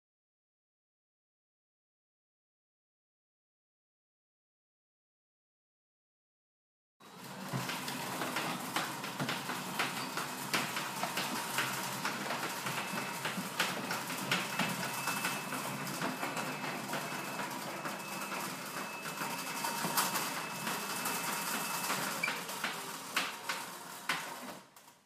随机的 " 木质的门 粗糙的公寓门打开关闭的锁扣
描述：门木多节公寓门打开关闭捕获与latch.flac